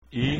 Давайте прослушаем произношение этих звуков:
i: